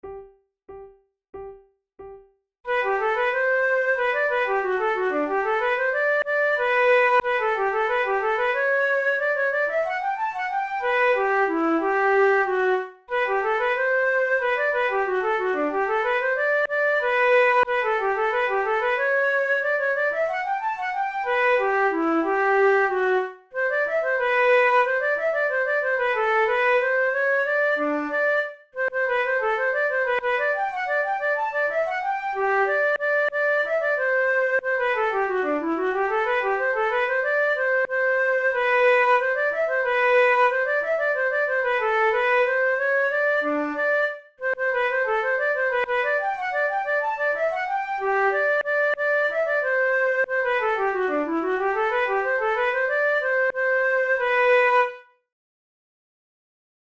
InstrumentationFlute duet
KeyG major
Time signature2/2
Tempo92 BPM
Classical, Written for Flute